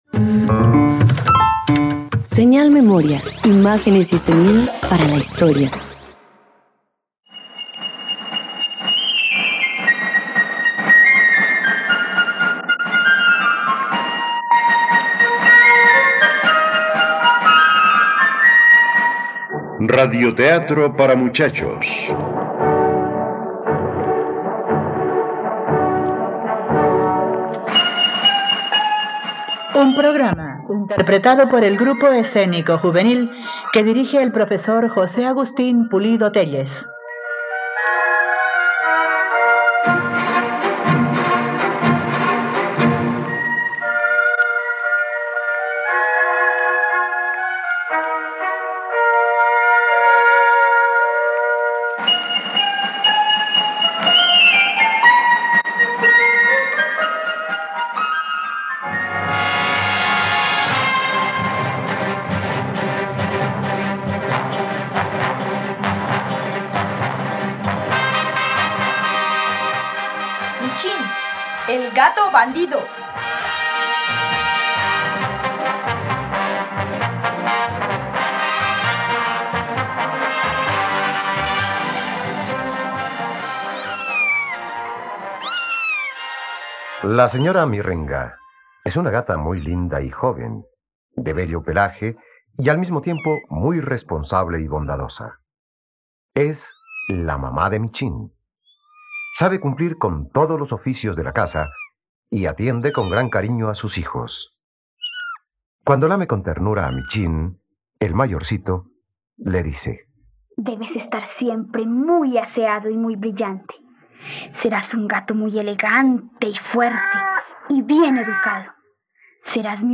Michín, el gato - Radioteatro dominical | RTVCPlay
..Radioteatros. Escucha la adaptación de la obra “El gato bandido” del escritor, poeta, fabulista y diplomático colombiano Rafael Pombo, disponible en RTVCPlay.